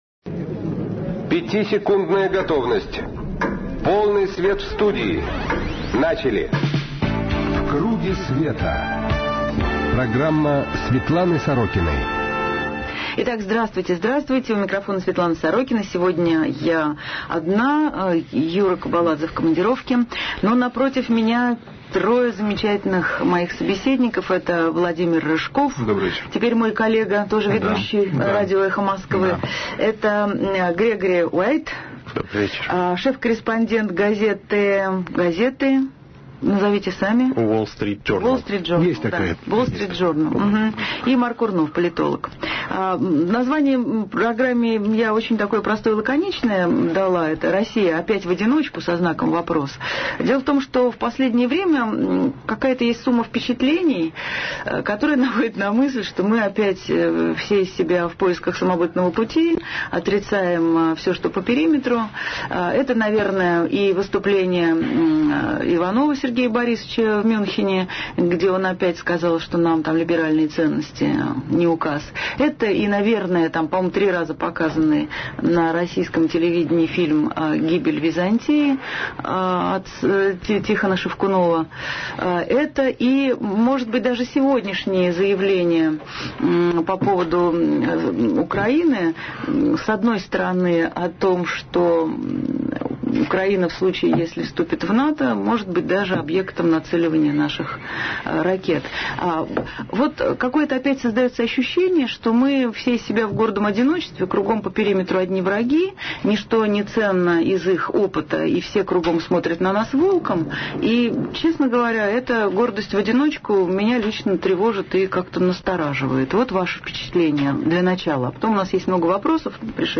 В КРУГЕ СВЕТА программа Светланы Сорокиной на радио «Эхо Москвы» 12 февраля 2008 г. Россия: опять в одиночку?